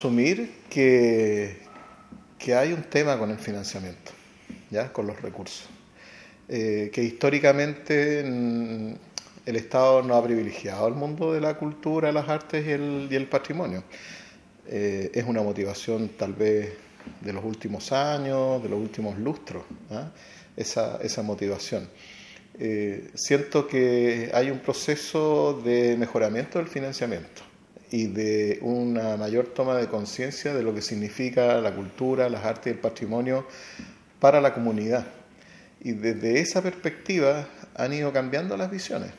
Conversatorio en el MUG: Avances y gestiones sobre patrimonio cultural
En la jornada expuso el director regional del Servicio Nacional de Patrimonio Cultural.